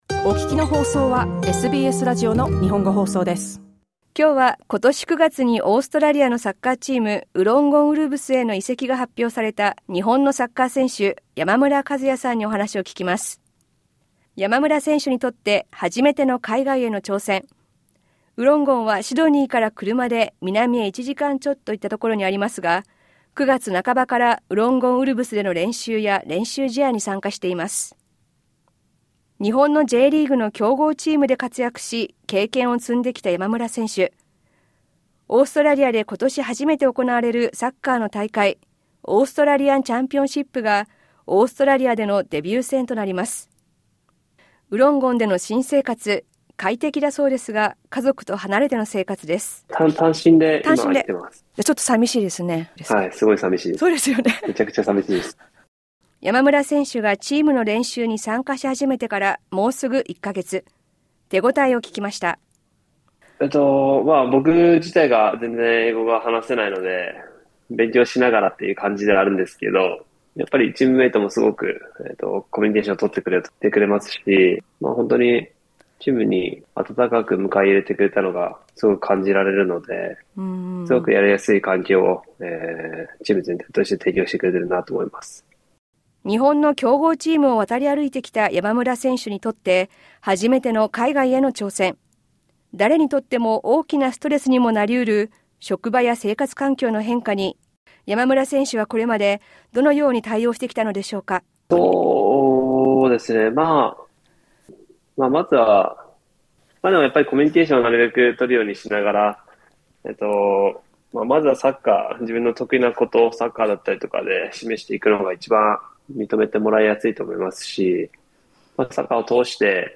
インタビューでは山村和也選手に、選手としての自分の強みや日豪の違い、移籍したウロンゴン・ウルブスなどについて聞きました。